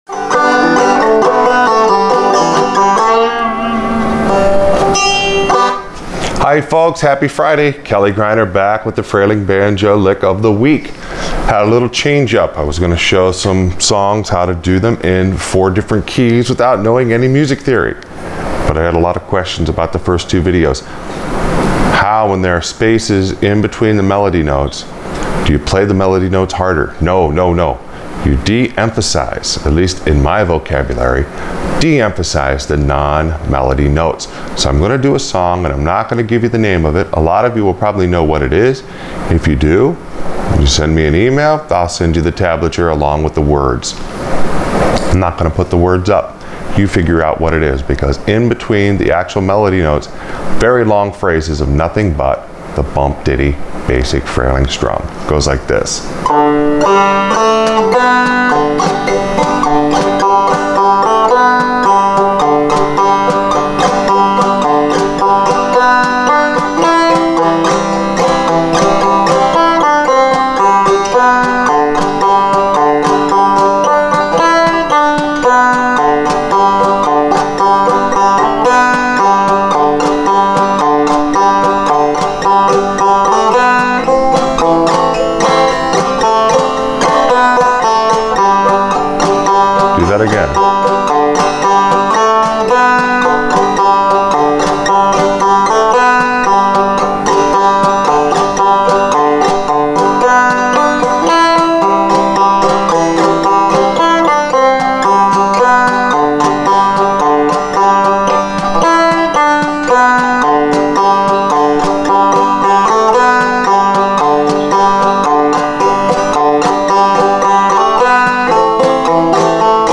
The trick is not to play the melody notes harder, but the empty spaces softer.